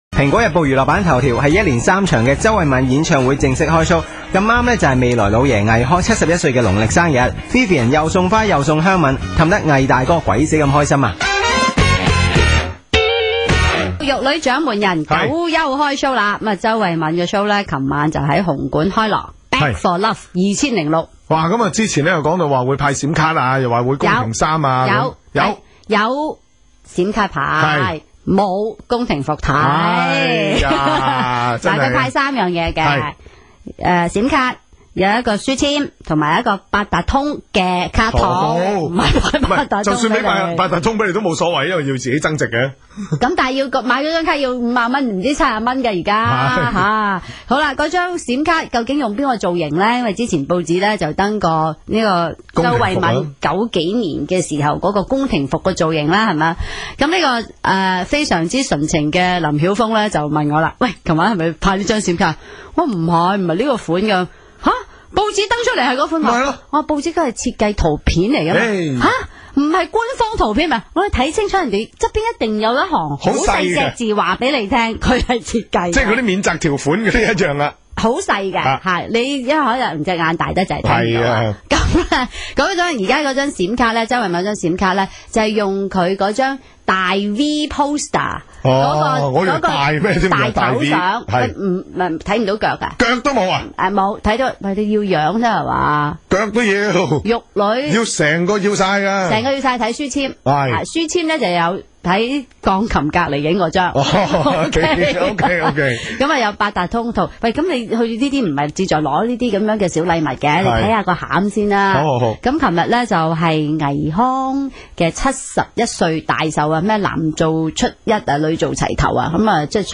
Entertainment news from Commercial Radio 881 (in Cantonese) - May 26, 2006; Posted on September 15, 2008